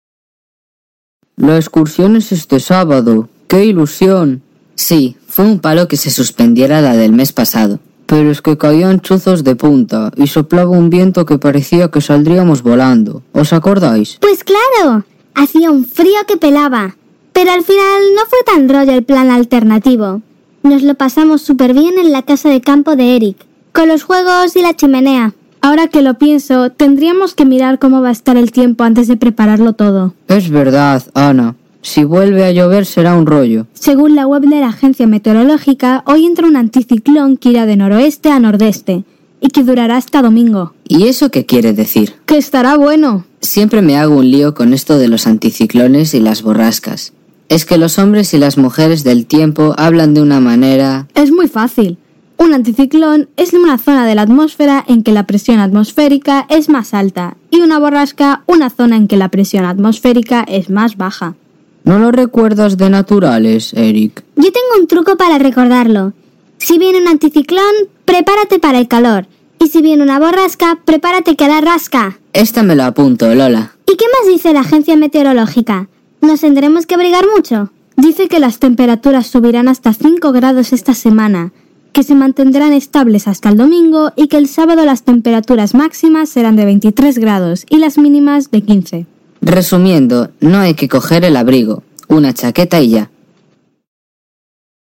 ESCUCHAMOS UNA CONVERSACIÓN CON DIFERENTES REGISTROS | ¿QUÉ PALABRAS DIFERENTES UTILIZÁIS ENTRE AMIGOS?